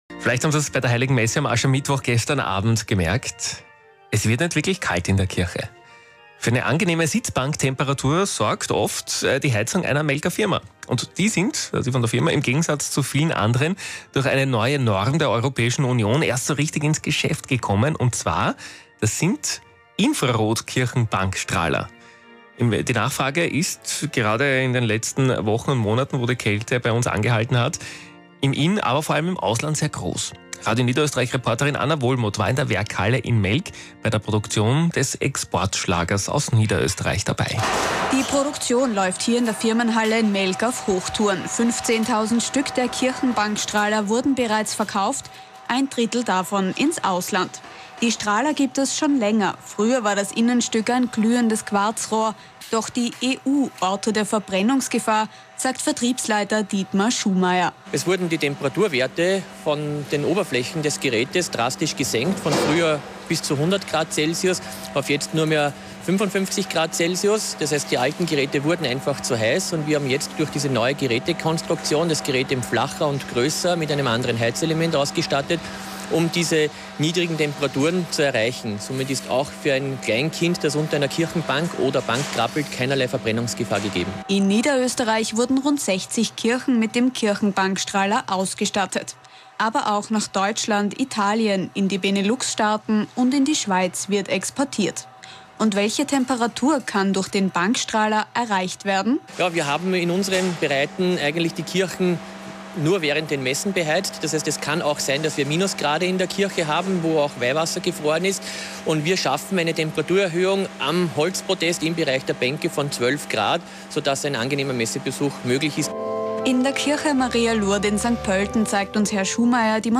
Radiosendung "Guten Morgen NÖ - Exportschlager Kirchenheizung", Sendetermin: 18.02.2010
kirche_radio.wma